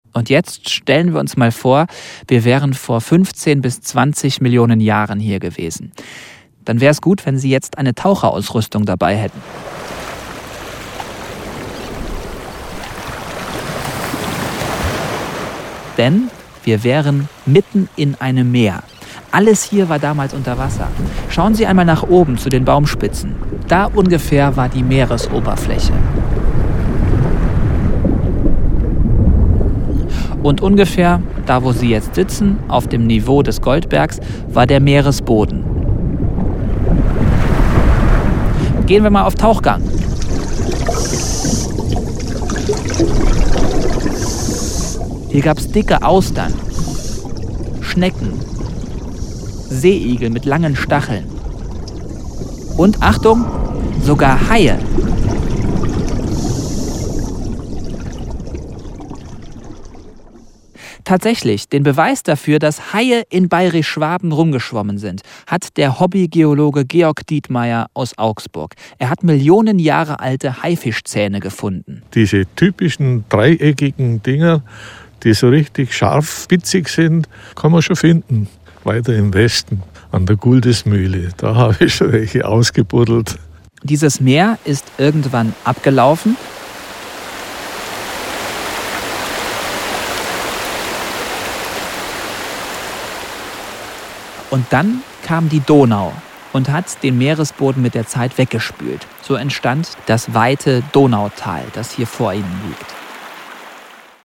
Wir folgen den Spuren großer Weltgeschichte, hören die bewegende Geschichte einer Waldkapelle und lauschen den Stimmen seltener Tierarten. Außerdem klären wir die Geheimnisse der Sagenwelt – von der versunkenen Goldburg bis zum legendären Goldschatz, der auf diesem Berg vergraben sein soll!